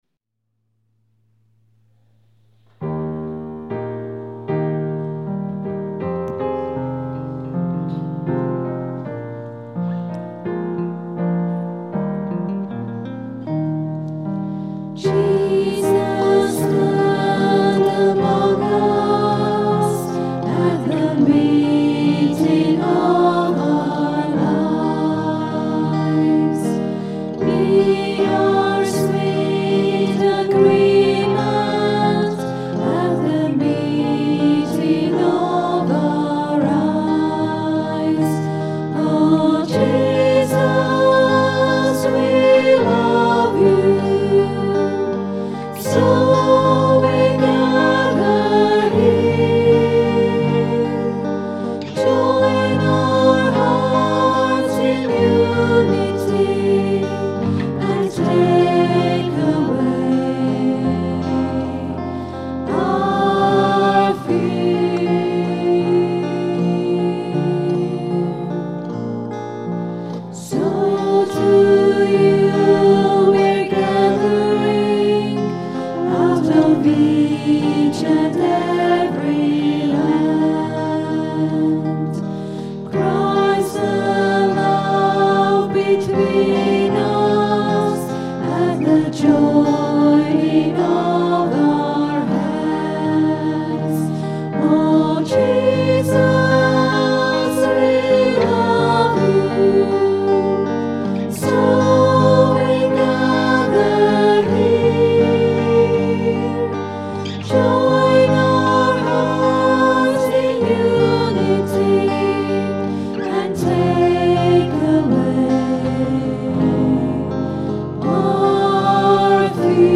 This gentle hymn is often sung at weddings. It was recorded at 10am Mass on Sunday 20th July 2008. Recorded on the Zoom H4 digital stereo recorder through a Behringer SL2442FX mixer. Sound editing and effects using Acoustica Mixcraft 4 audio processing software.